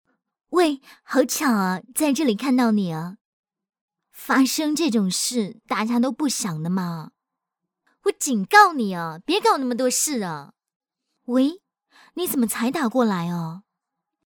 女218-模仿 TVB腔
女218-温柔甜美 港台腔
女218-模仿 TVB腔.mp3